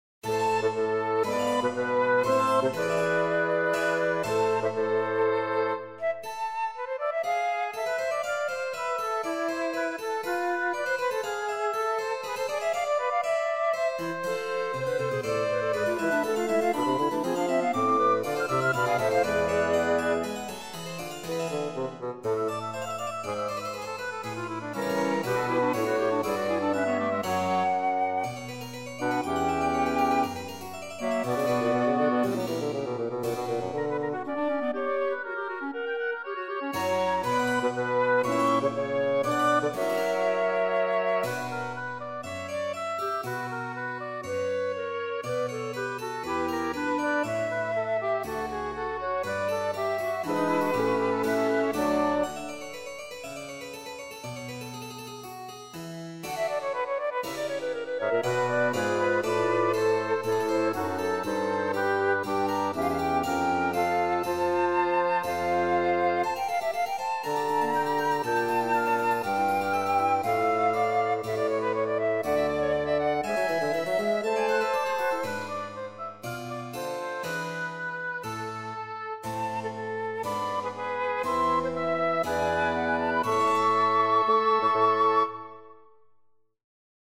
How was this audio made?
I have recently acquired my first sample libraries in the form of Garritan Personal Orchestra and EastWestQuantumLeap Symphony Orchestra (Silver Edition).